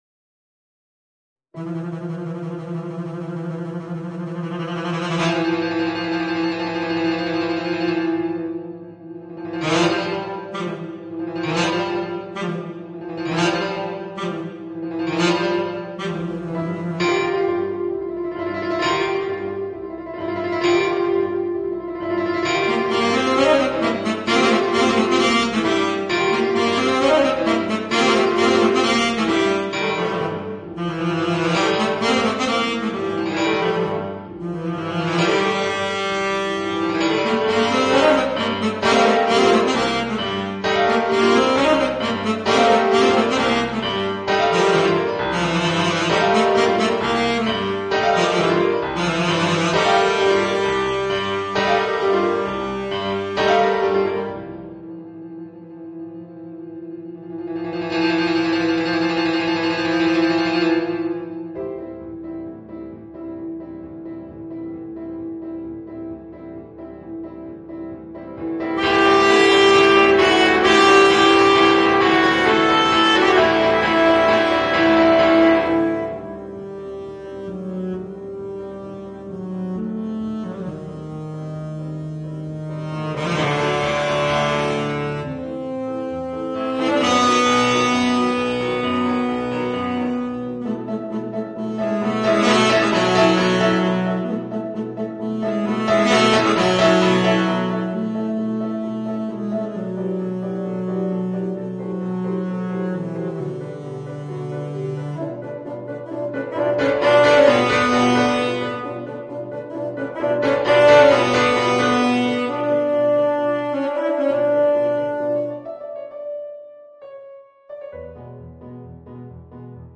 Voicing: Tenor Saxophone and Piano